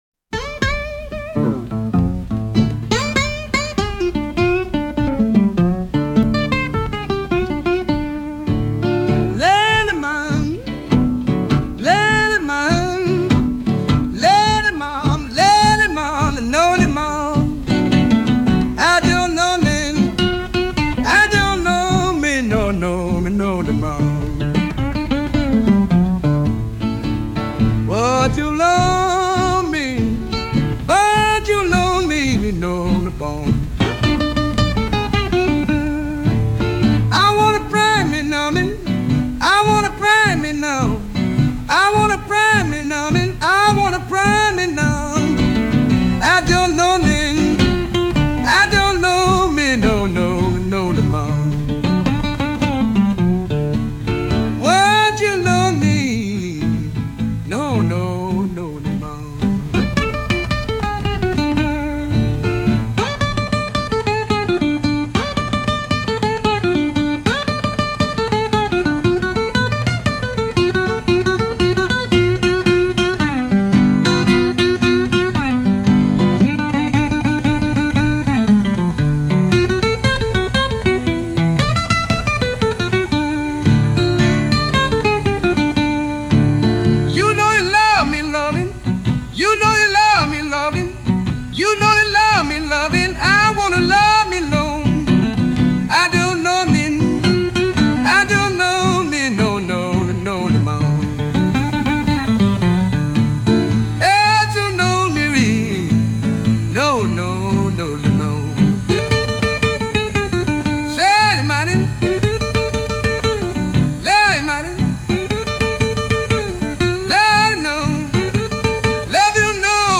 Instrumental - Real Liberty Media Dot Com- 4 mins.mp3